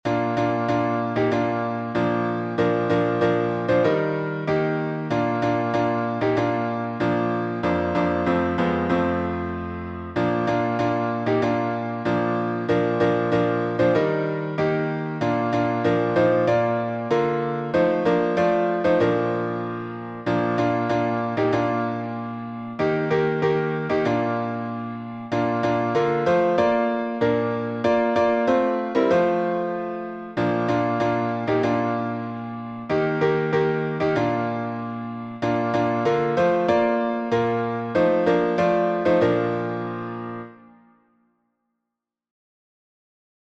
Bringing in the Sheaves — B flat major.